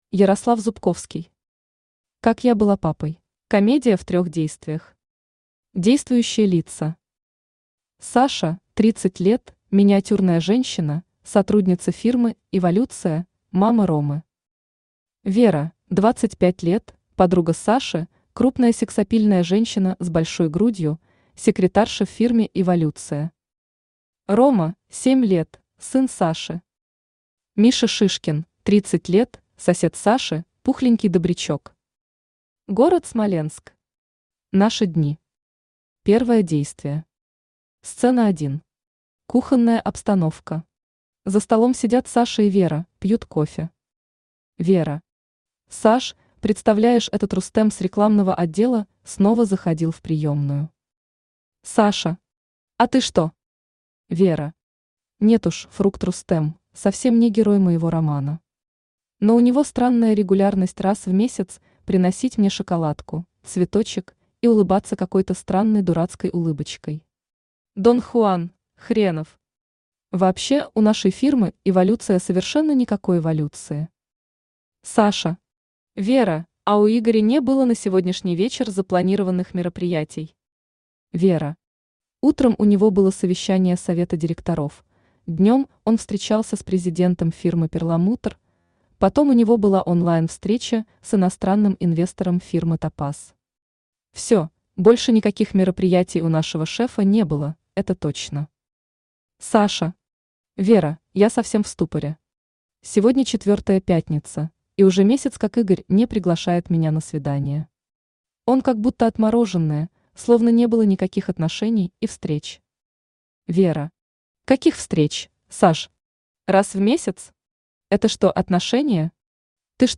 Аудиокнига Как я была папой | Библиотека аудиокниг